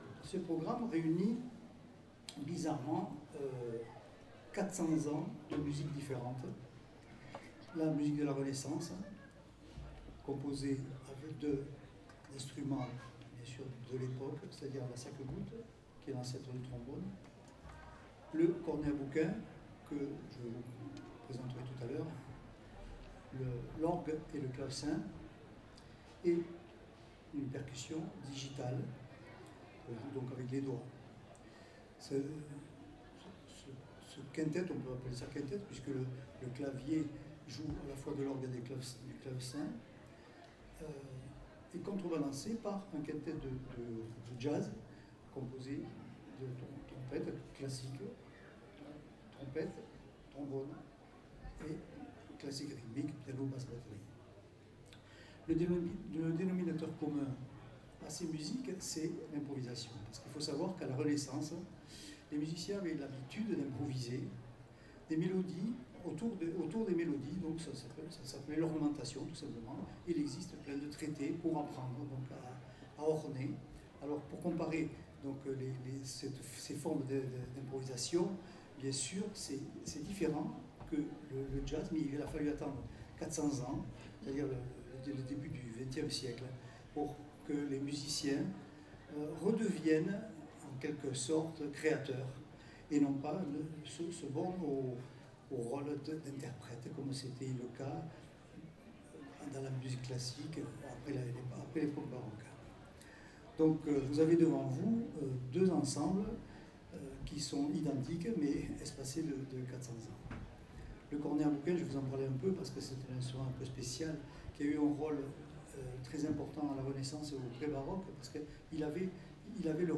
Le premier concert de cette soirée du samedi 12 août, aura été une des grandes originalités de cette dix-neuvième édition du festival.
Présentation du concert Casque